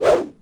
FootSwing1.wav